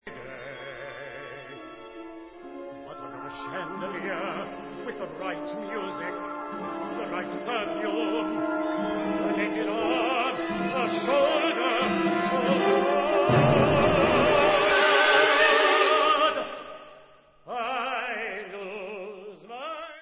Recording: OPERA